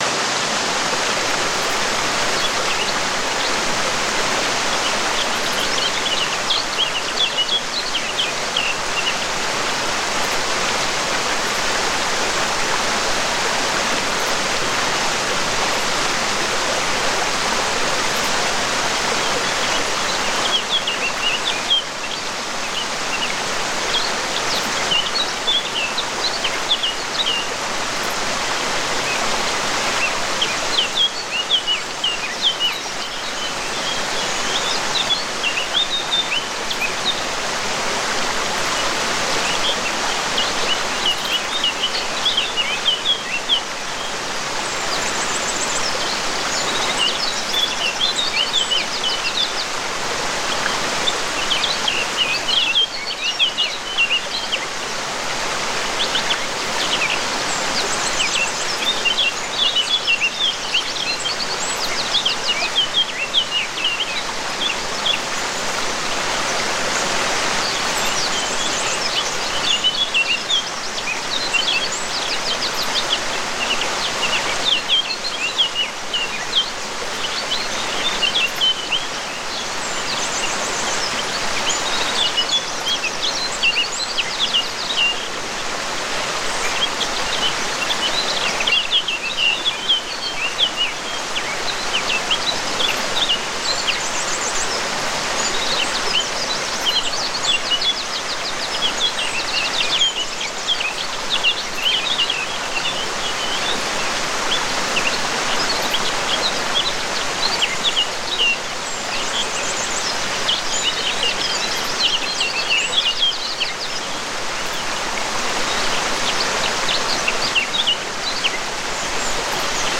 10H-TRAUMSCHLAF-GEHEIMNIS: Herbst-Wasserfall-Bachklang funktioniert JEDE Nacht